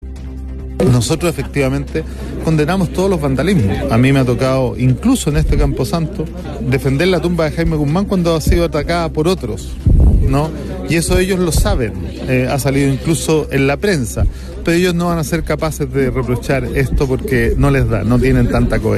El alcalde de Recoleta, Daniel Jadue, declaró estar en contra de todo tipo de violencia, incluso defendiendo la tumba de Jaime Guzman cuando esta fue atacada, e hizo un llamado a los responsables a tener más coherencia en sus actos.